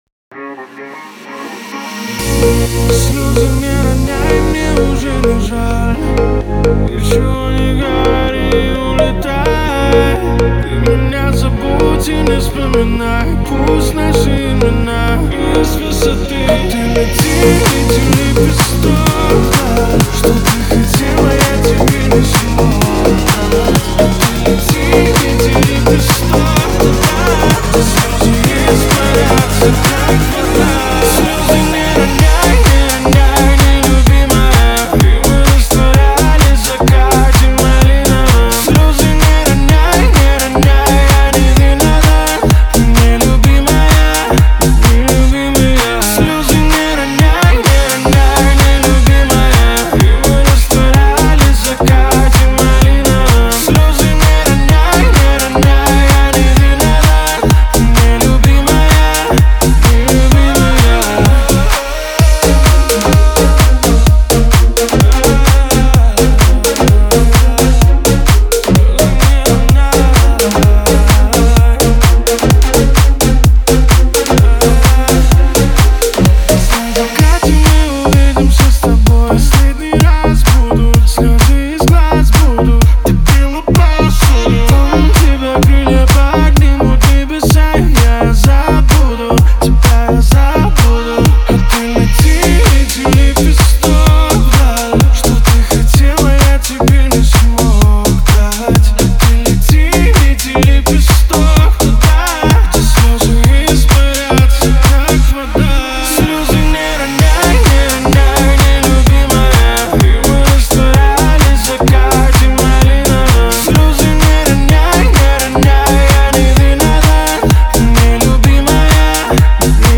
это захватывающее произведение в жанре электронной музыки